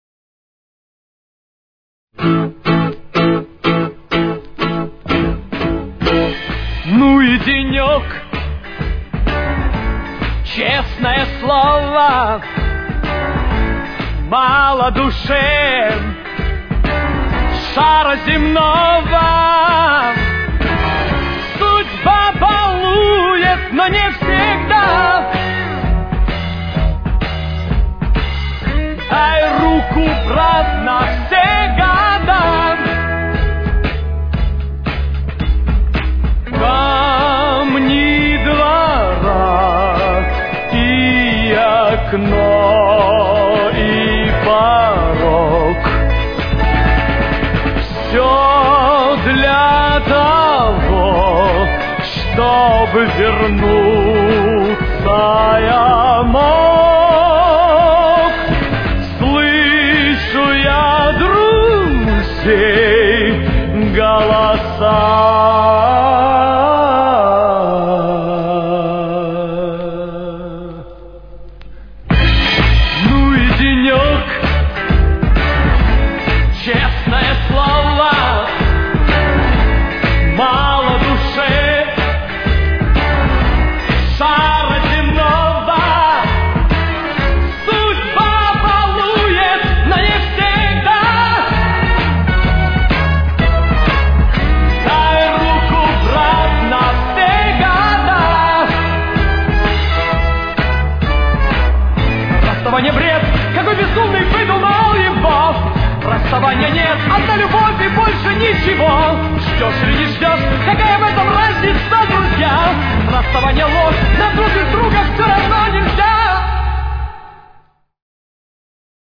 Темп: 65.